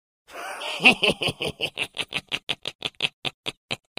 阴险奸笑音效_人物音效音效配乐_免费素材下载_提案神器
阴险奸笑音效免费音频素材下载